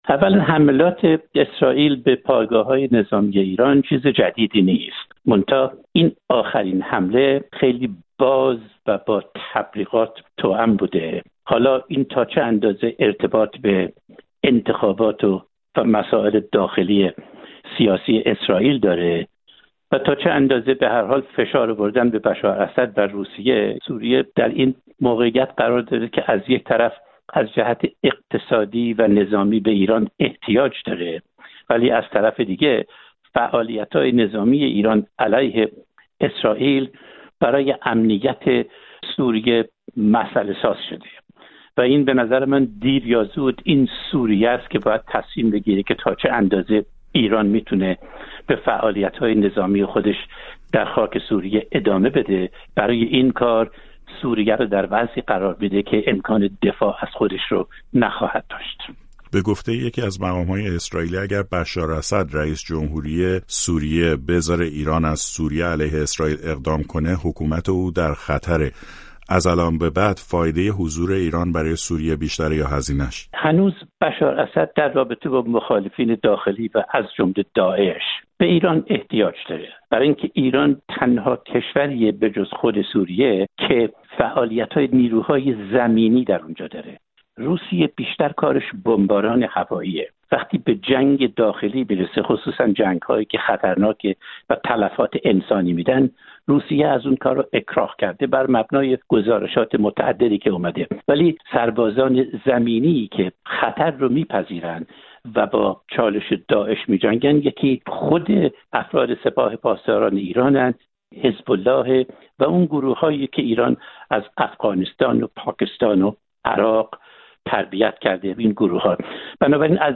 نخست‌وزیر اسرائیل، روز دوشنبه گفت که حملات هوایی روز دوشنبه اسرائیل به سوریه عمدتاً مواضع نظامی برپا شده توسط ایران را هدف قرار داده و هشدار داد که جمهوری اسلامی با عواقب تهدید به نابودی اسرائیل مواجه خواهد شد. گفت‌وگوی